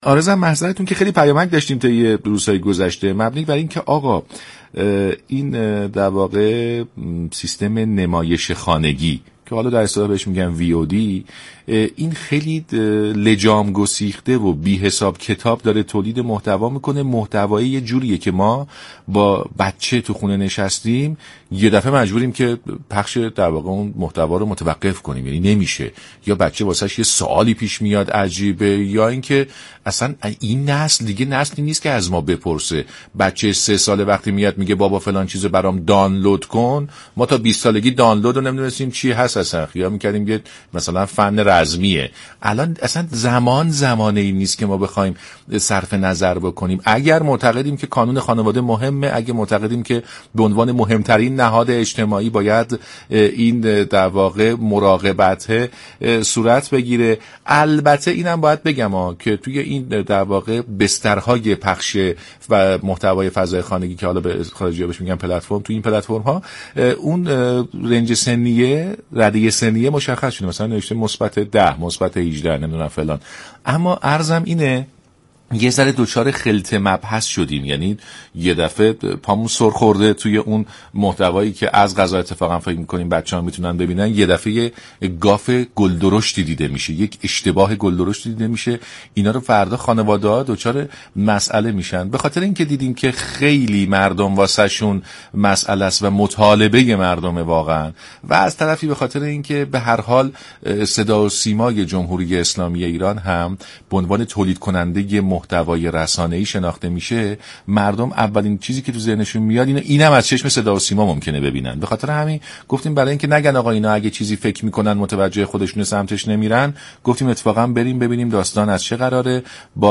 بیژن نوباوه نماینده مجلس در سلام صبح بخیر رادیو ایران گفت: برای استفاده بهینه از فضای مجازی باید در كشور اینترنت نونهالان و نوجوانان راه اندازی شود.